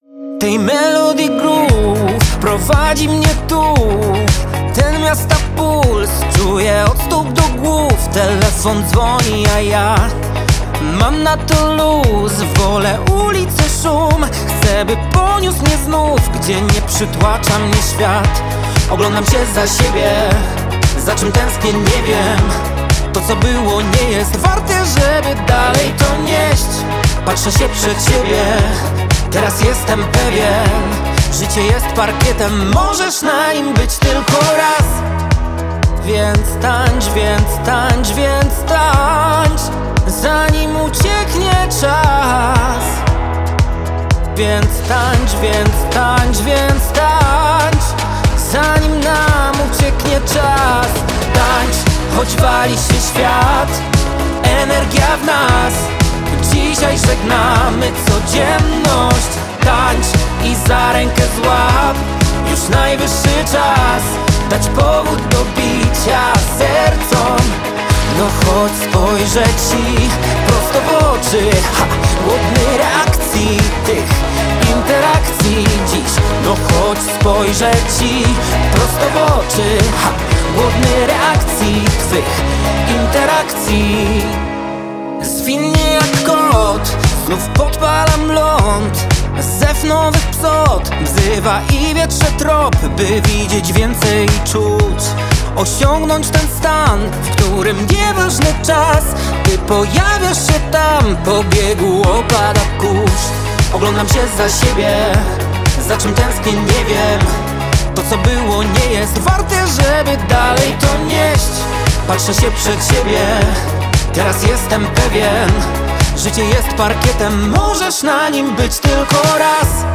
świetny radiowy pop!